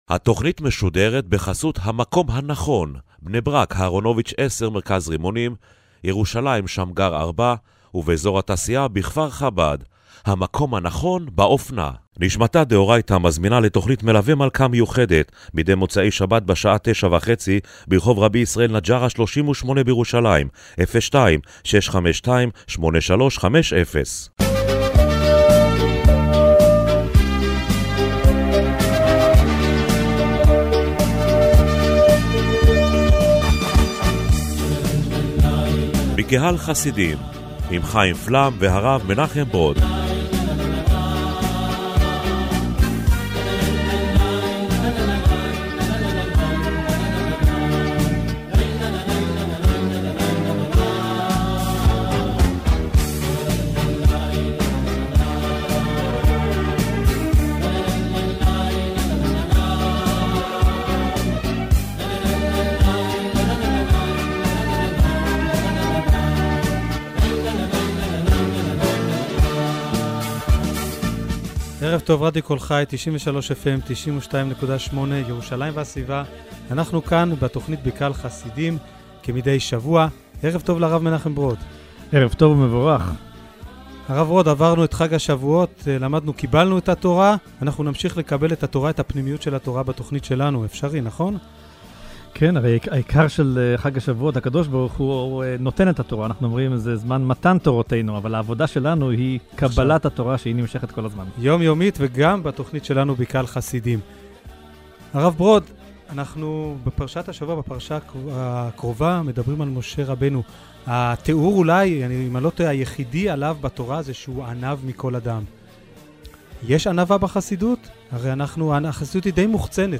במרכז תכנית הרדיו השבועית 'בקהל חסידים' השבוע עמדה ההתייחסות של החסידות לנושא הענווה. בפינה 'הסיפור החסידי ומה שמאחוריו' הובא סיפור המסביר למה לפעמים אין הצדיק נענה לבקשת ברכה.